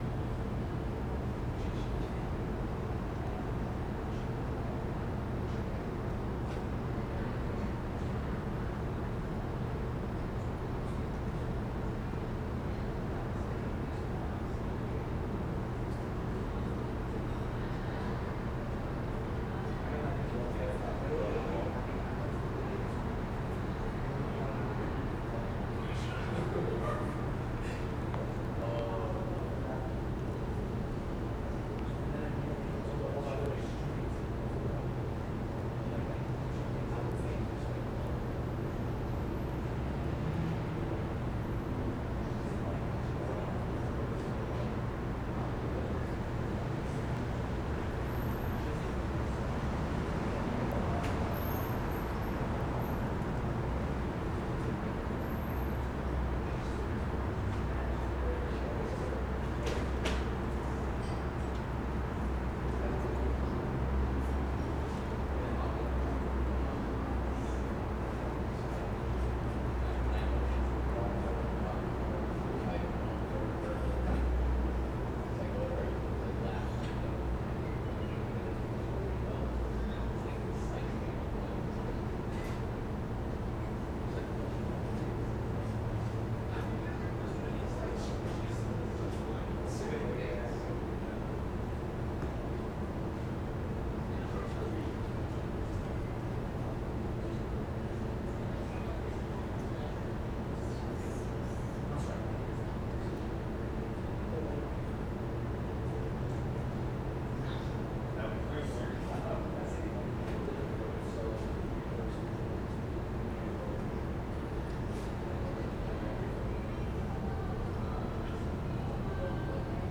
QUIET STREET Back alley, people chatting, ST.wav